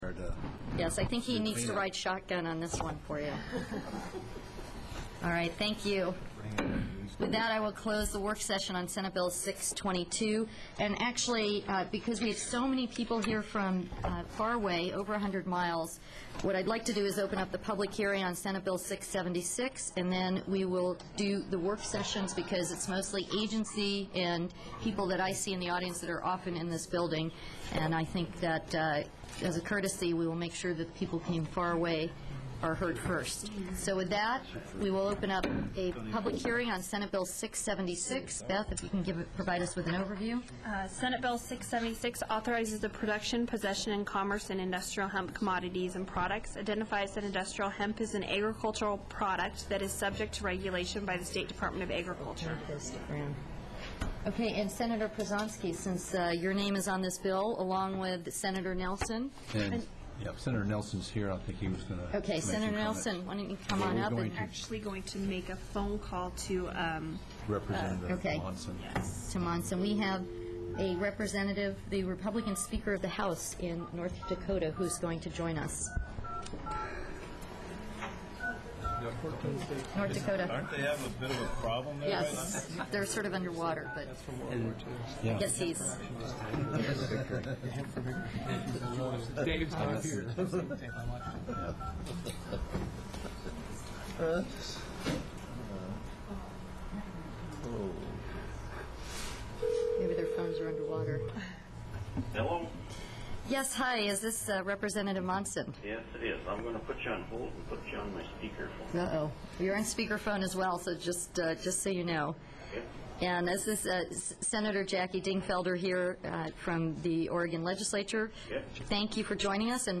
Public Hearing held on 3/26/09.